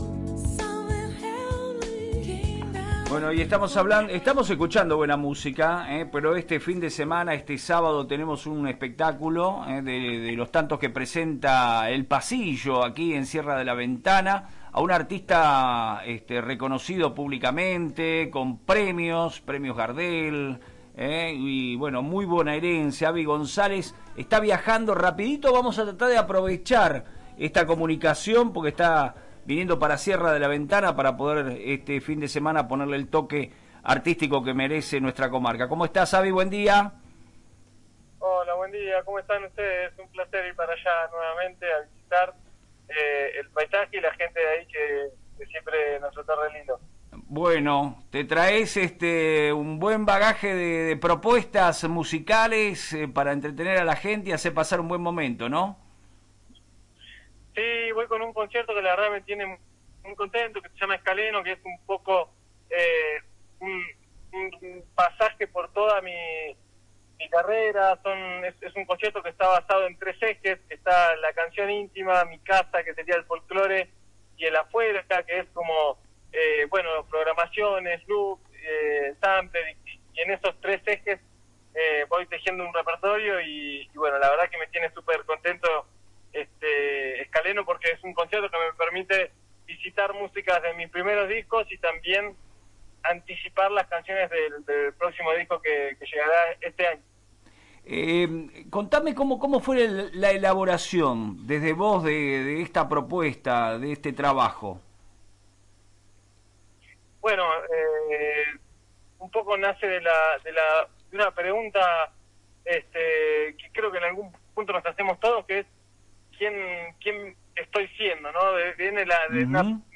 su entrevista en FM Reflejos de Sierra de la Ventana